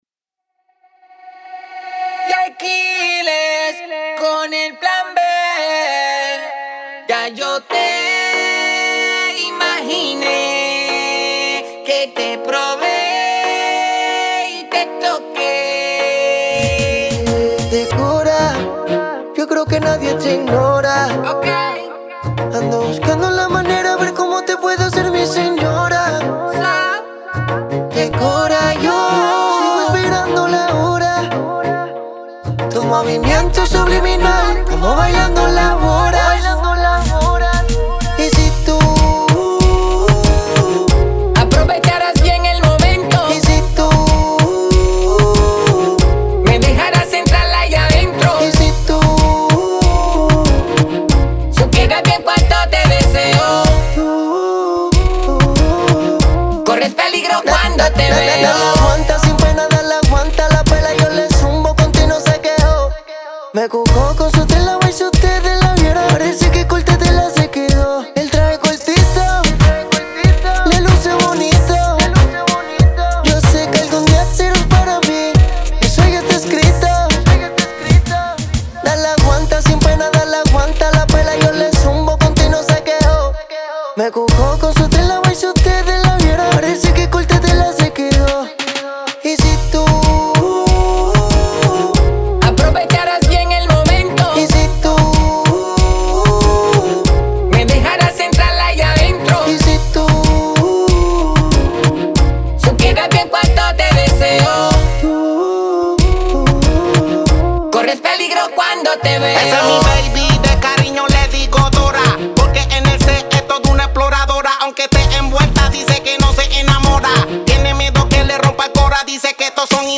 зажигательная реггетон-композиция